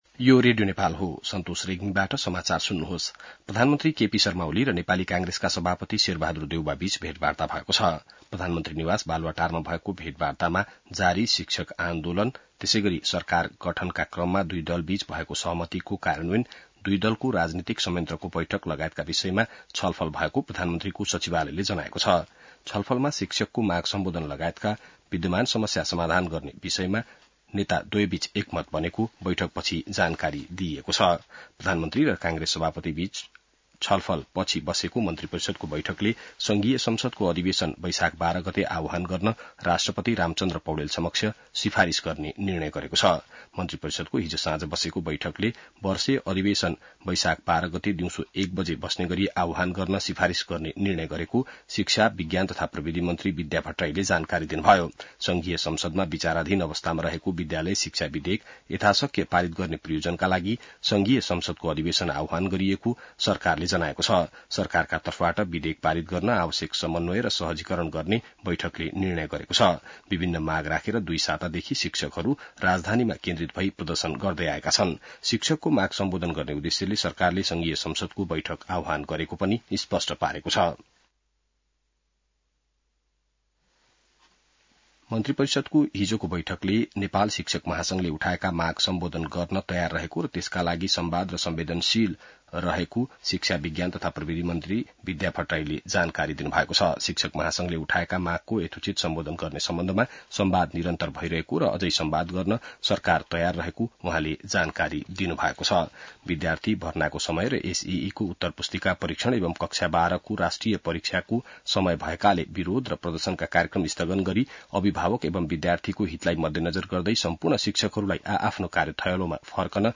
बिहान ६ बजेको नेपाली समाचार : ३ वैशाख , २०८२